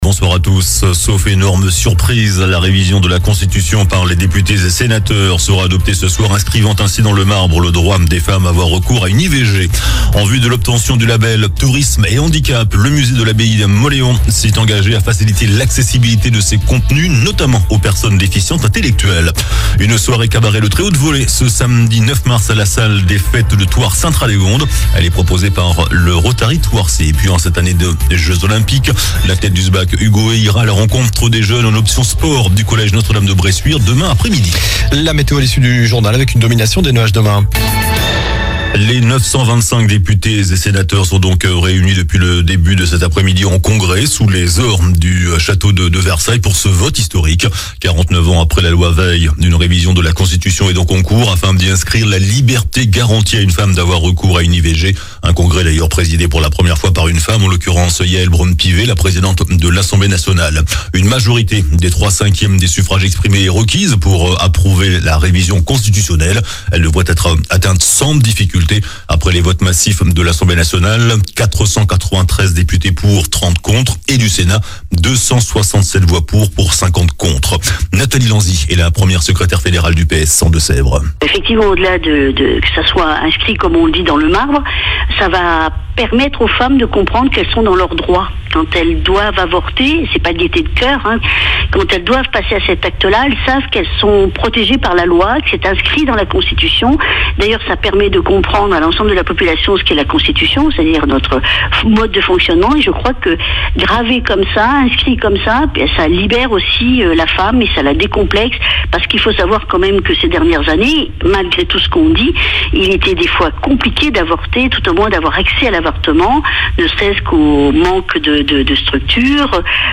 JOURNAL DU LUNDI 04 MARS ( SOIR )